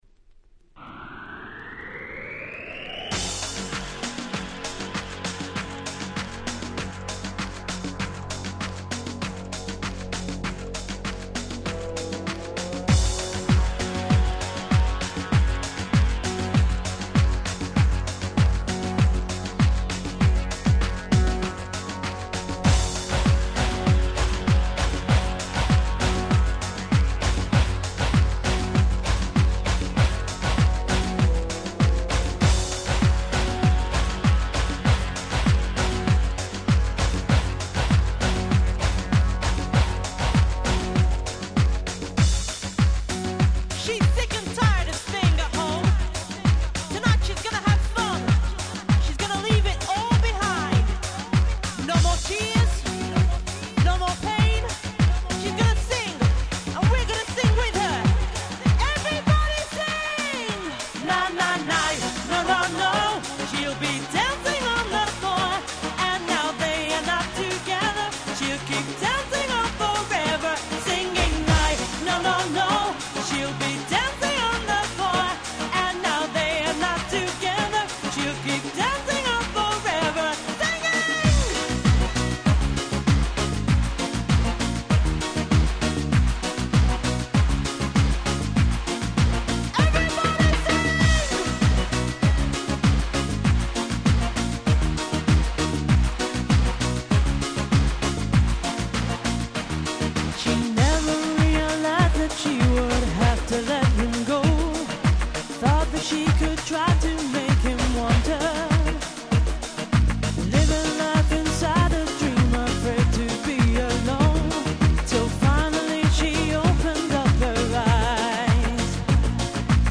97' Nice EU R&B !!!
「ナッナッナ〜」とキャッチーなサビで憤死も免れません！！(笑)